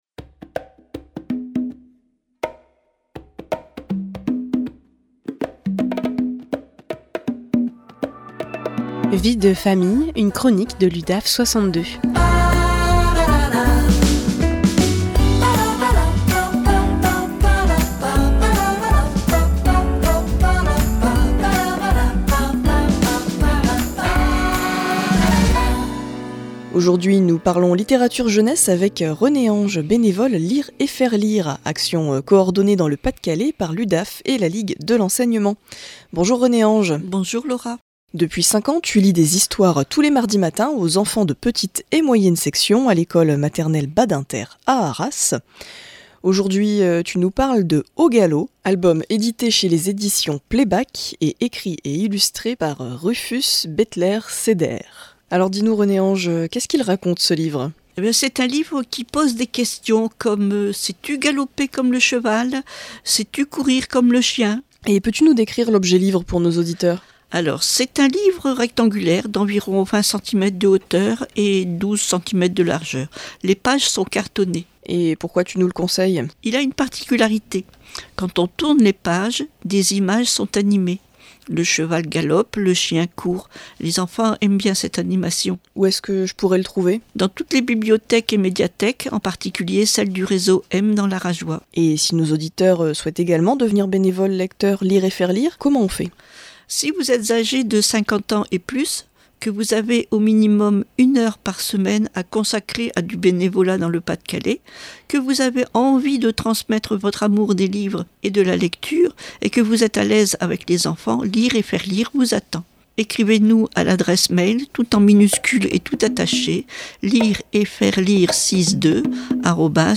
Vie de Famille, une chronique de l’Udaf62 en live sur RADIO PFM 99.9